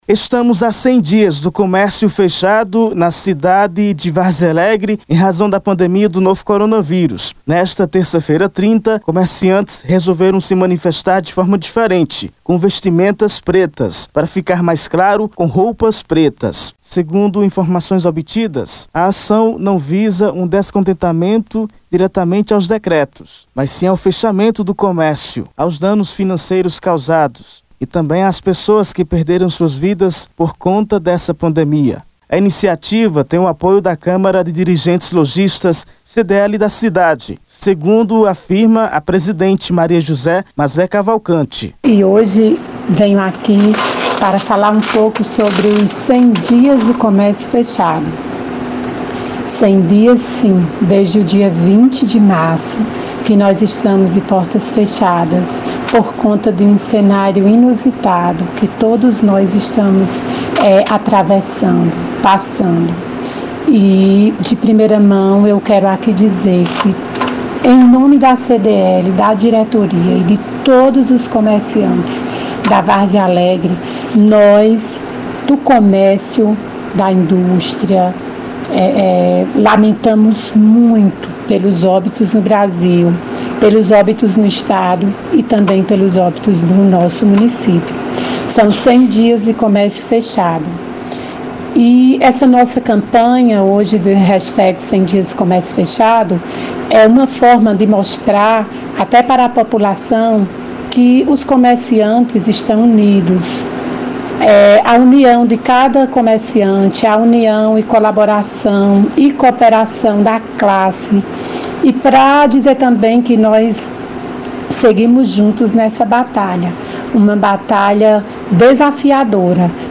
Estamos há 100 dias do comercio fechado na cidade de Várzea Alegre em razão da pandemia do novo coronavírus. Acompanhe na reportagem: